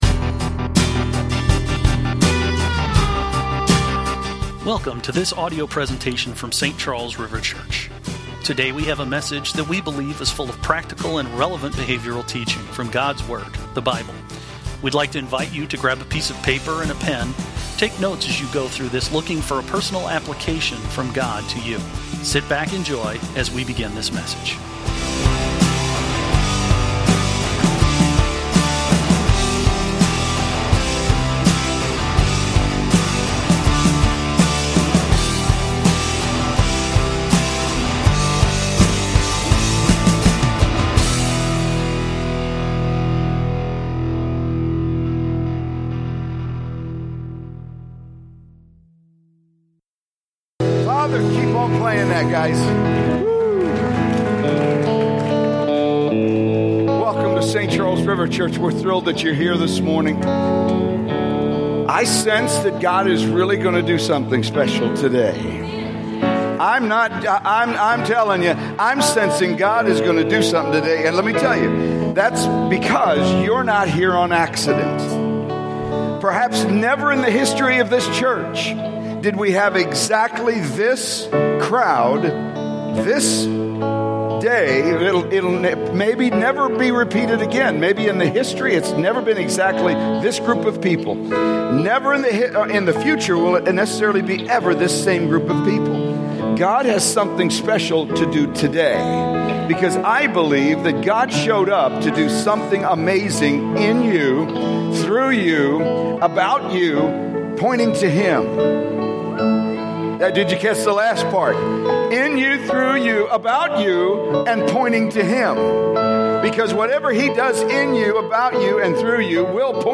Weekly podcast recorded Sunday mornings during the Celebration Service at Saint Charles River Church in O'Fallon, Missouri.